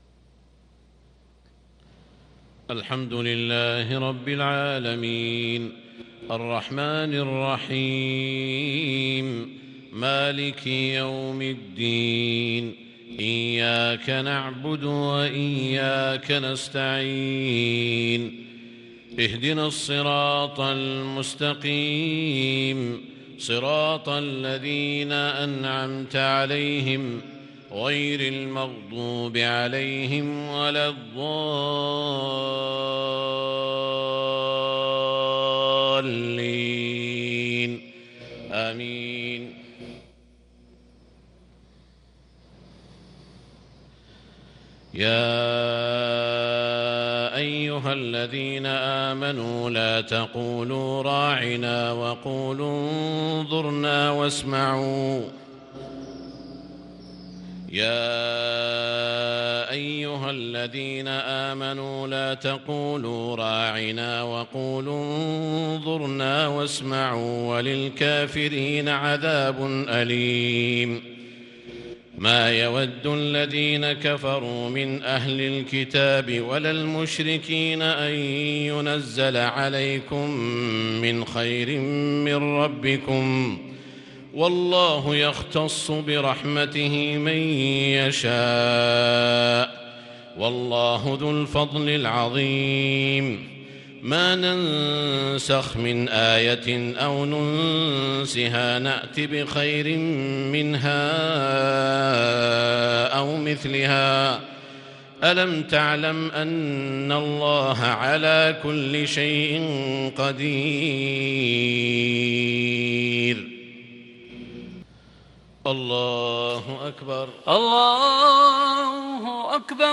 صلاة المغرب للقارئ سعود الشريم 18 ربيع الآخر 1443 هـ
تِلَاوَات الْحَرَمَيْن .